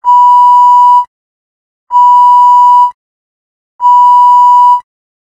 box_alarm.ogg